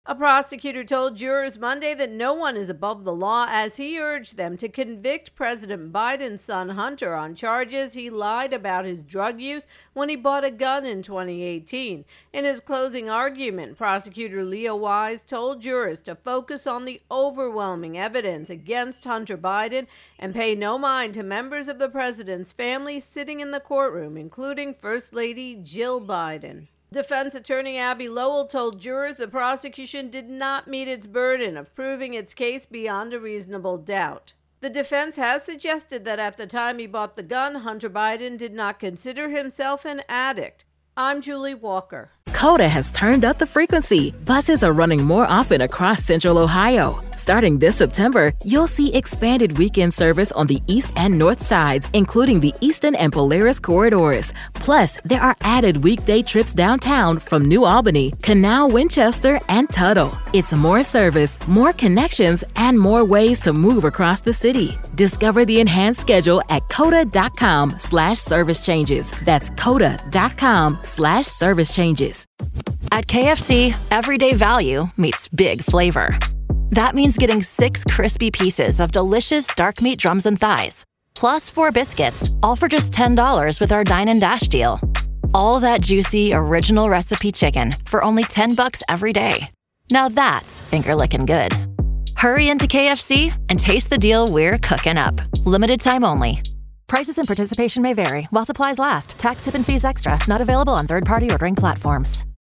reports on closing arguments in Hunter Biden's gun case.